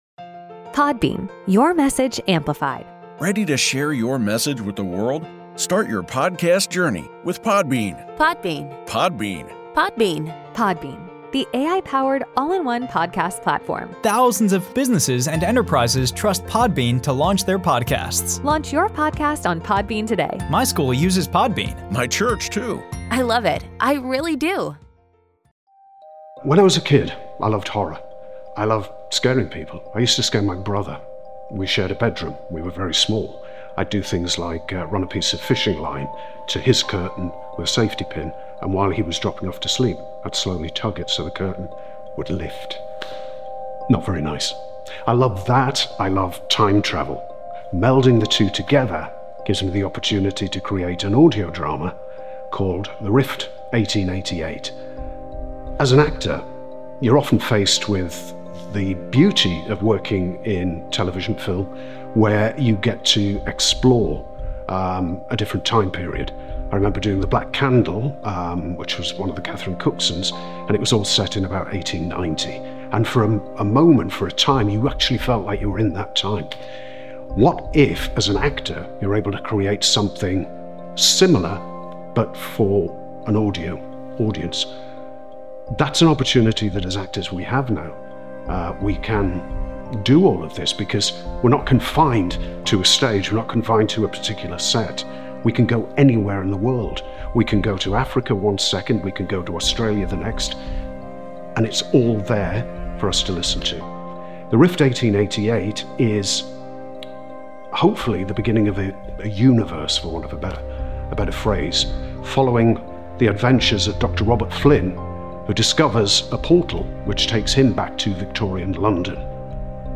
An Audio Drama Series Time has always marched forward unstoppable, unchangeable until now.